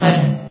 S.P.L.U.R.T-Station-13 / sound / vox_fem / button.ogg
button.ogg